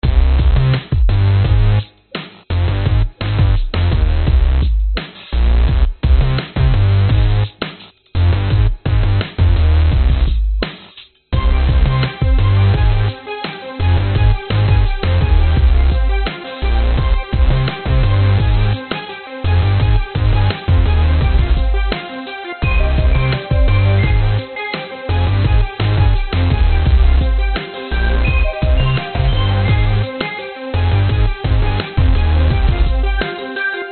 重型说唱/RnB循环包85
描述：沉重的说唱/Rn'B循环包，85 bpm。